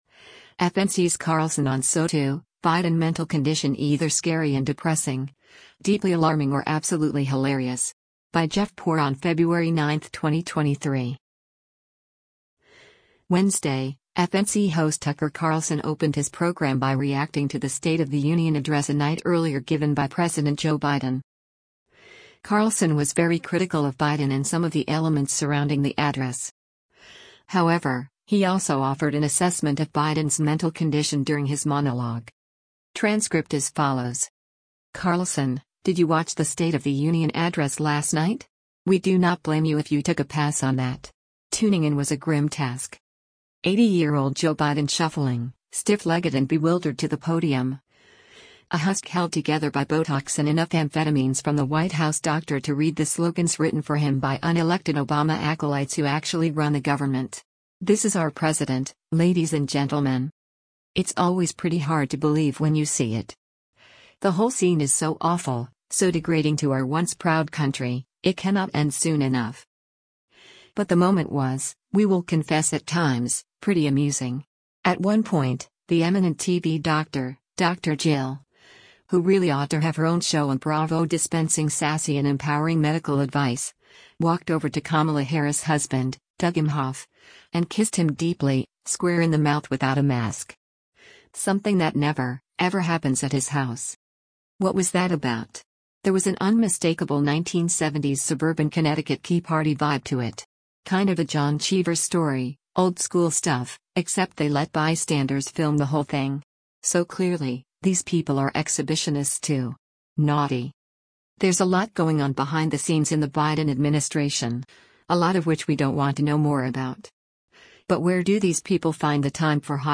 However, he also offered an assessment of Biden’s mental condition during his monologue.